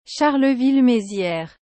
Charleville-Mézières (French pronunciation: [ʃaʁləvil mezjɛʁ]